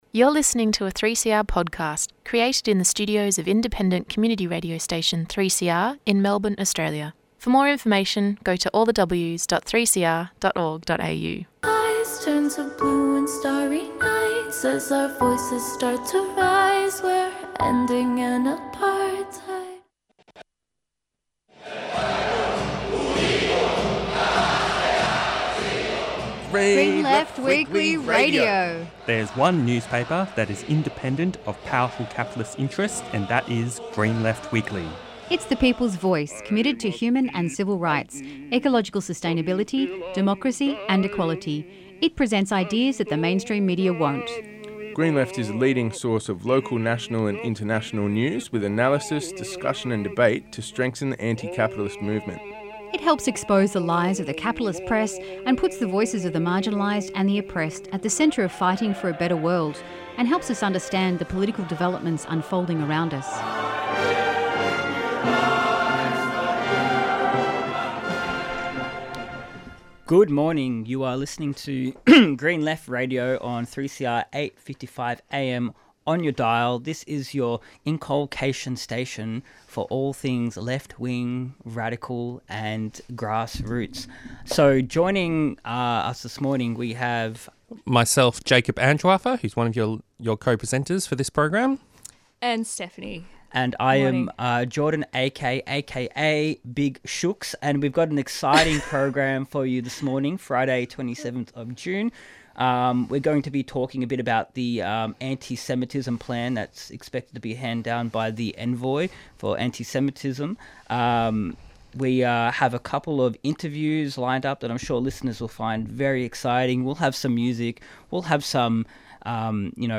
The presenters discuss the killing of seven aid workers in Gaza by Israel and the shameless hypocritical response from the Albanese government. Interviews and Discussion